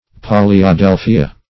Search Result for " polyadelphia" : The Collaborative International Dictionary of English v.0.48: Polyadelphia \Pol`y*a*del"phi*a\, n. pl.
polyadelphia.mp3